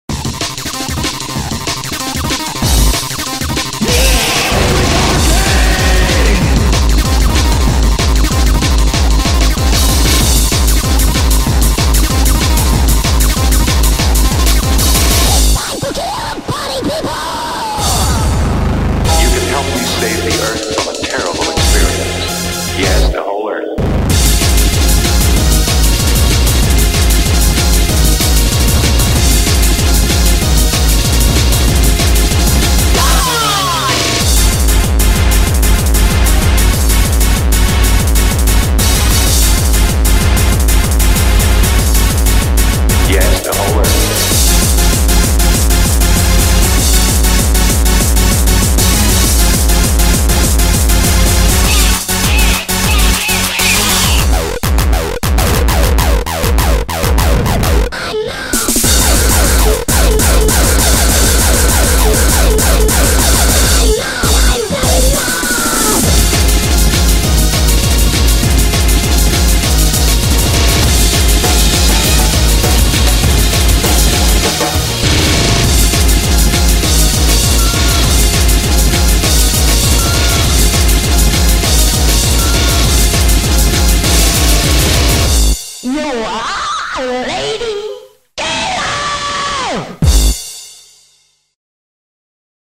BPM190-200
Audio QualityPerfect (Low Quality)